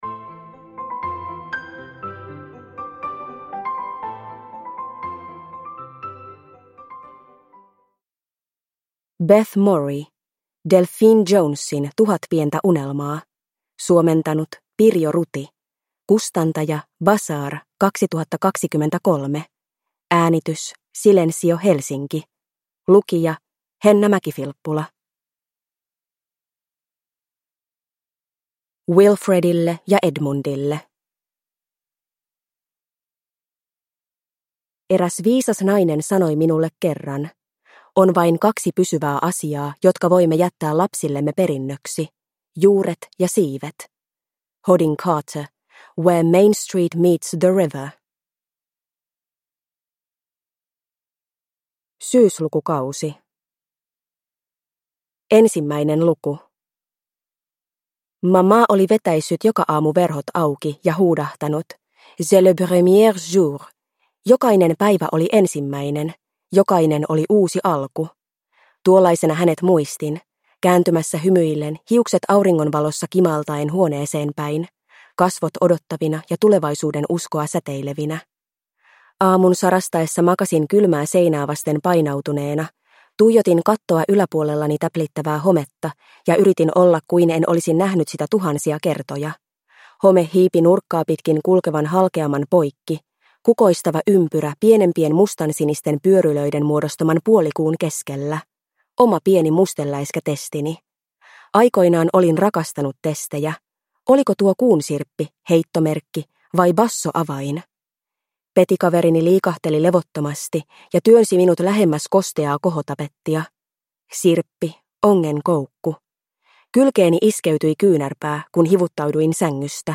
Delphine Jonesin tuhat pientä unelmaa – Ljudbok – Laddas ner